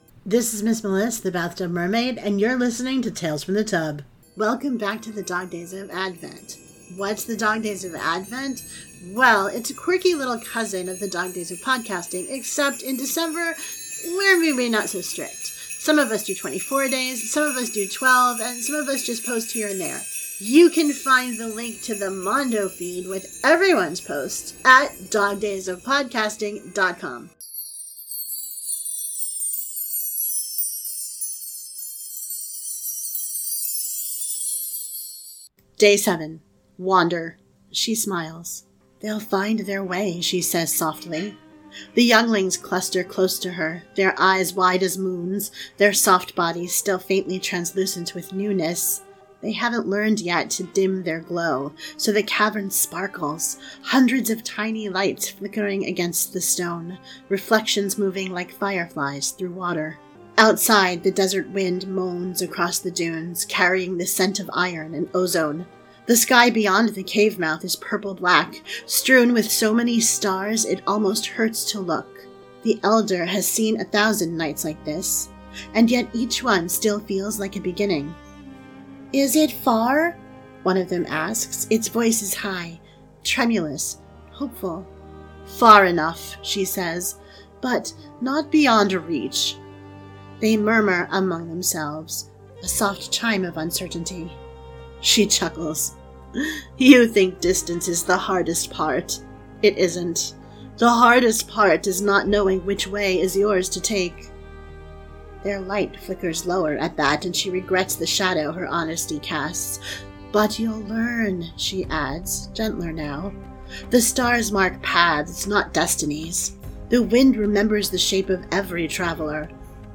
• Sound Effects and Music are from Freesound and UppBeat